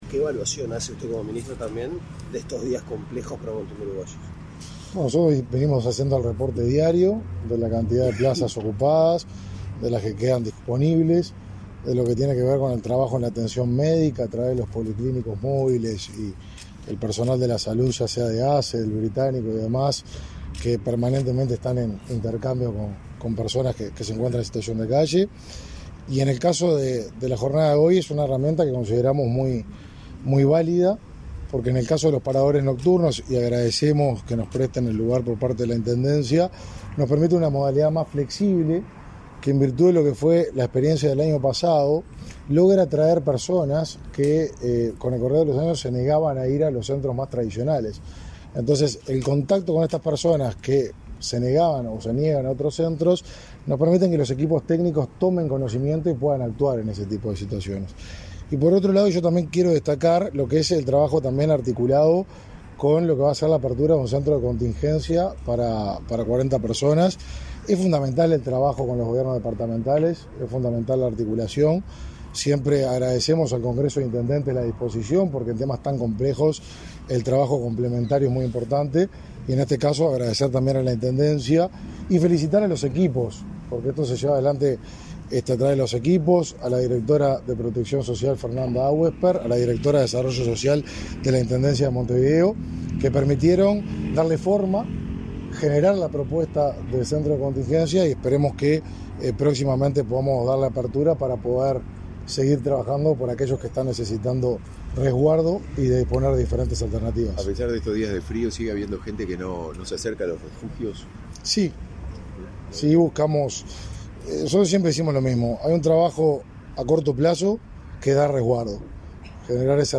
Declaraciones a la prensa del ministro de Desarrollo Social, Martín Lema
Tras el evento, Lema efectuó declaraciones a la prensa.